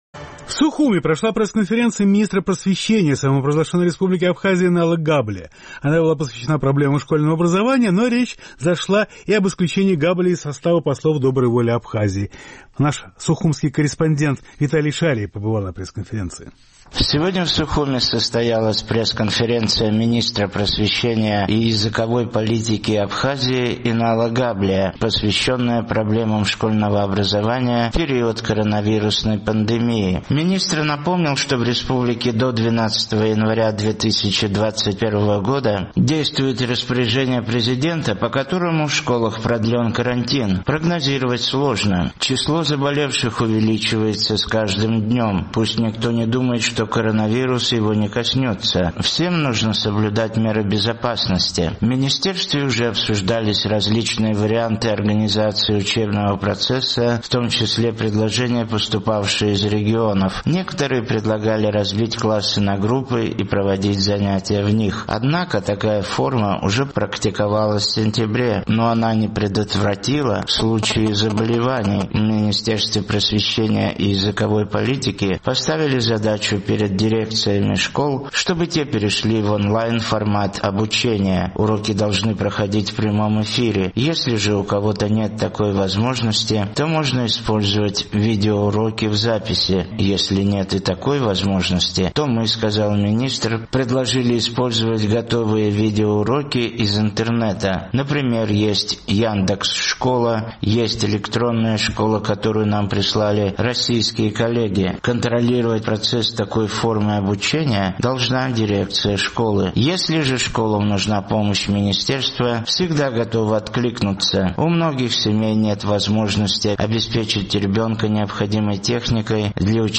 Сегодня в Сухуме состоялась пресс-конференция министра просвещения и языковой политики Абхазии Инала Габлия, посвященная проблемам школьного образования в период коронавирусной пандемии.